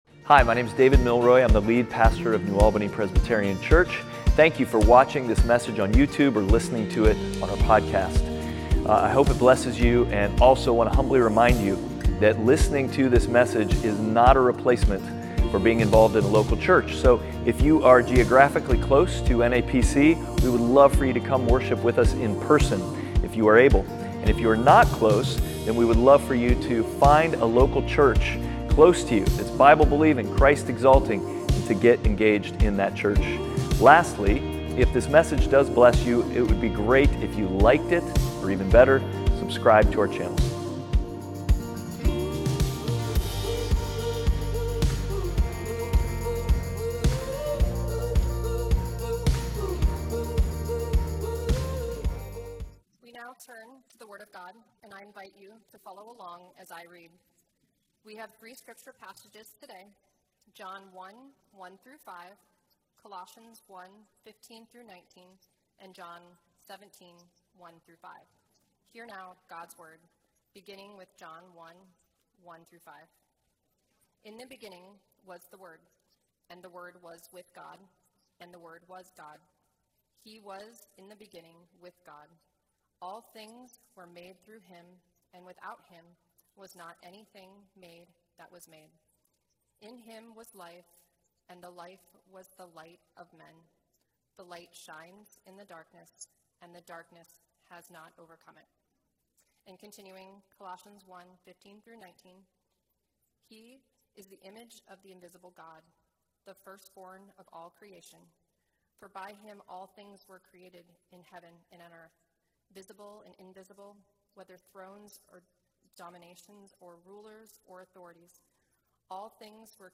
Passage: John 1:1-5, Colossians 1:15-19, John 17:1-5 Service Type: Sunday Worship